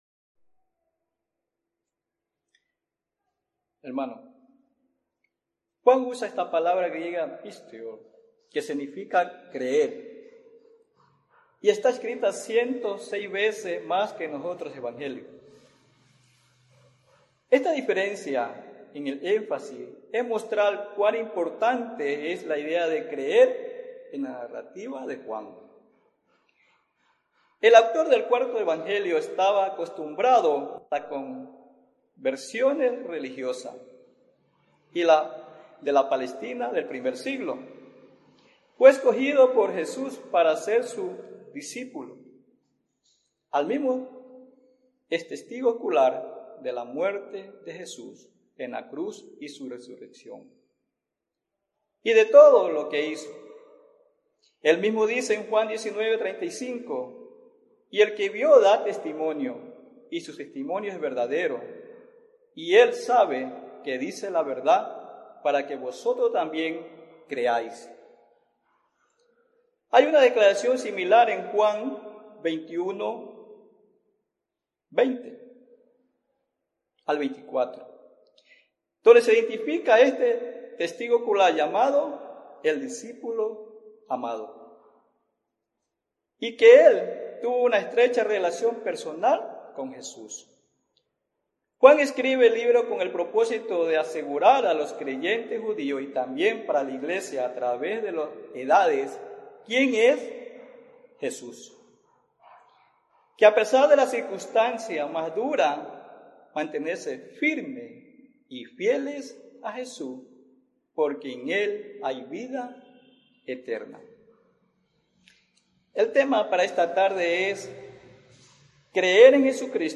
Tipo: Sermón Bible Text: Juan 20:30-31, Juan 21:20-25.